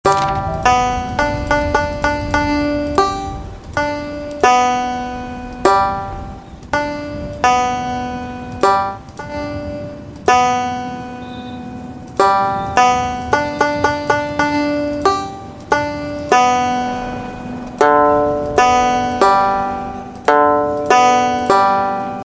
нужно выучить положение нот для воспроизведение на банджо.